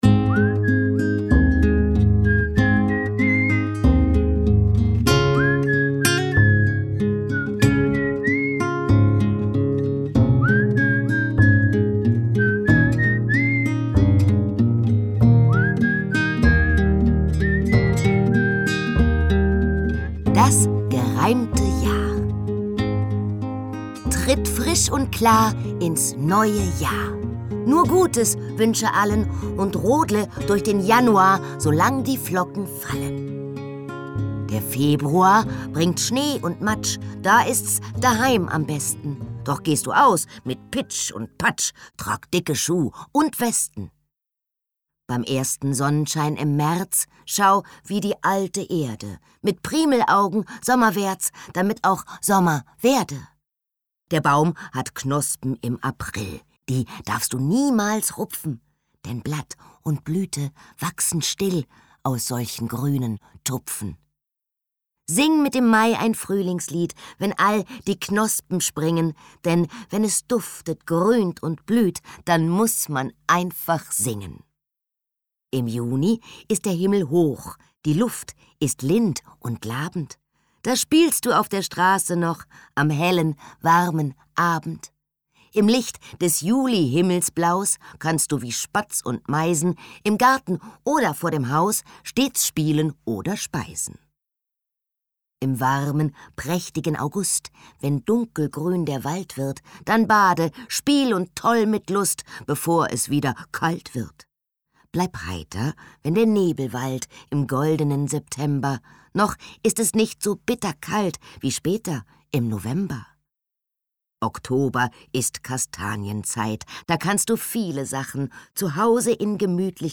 In dieser Hörprobe erwartet dich das Gedicht „Das gereimte Jahr“ – ein spielerischer Einstieg in Reime, Rhythmus und Fantasie.